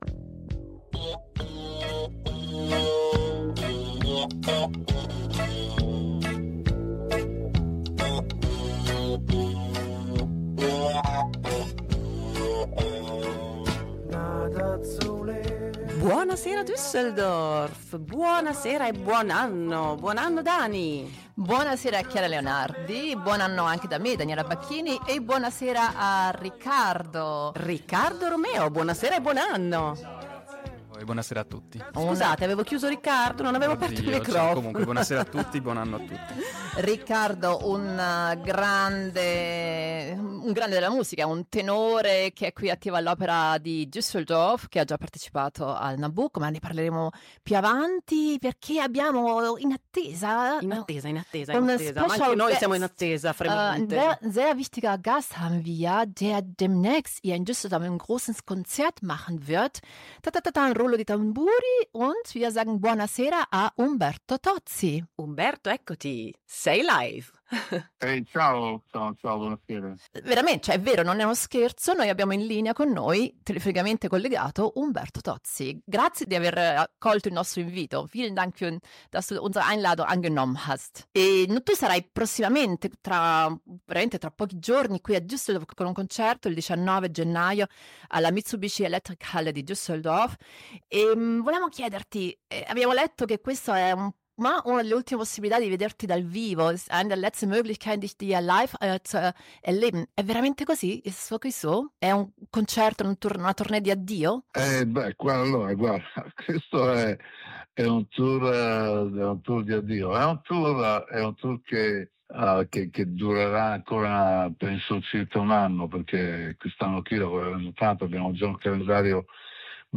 Das Konzert am 19. Januar 2025 war einer der letzten Live-Auftritte des italienischen Pop-Rock-Musikers. Im Interview berichtet er darüber, was es aus seiner Sicht für so eine lange und erfolgreiche Karriere braucht.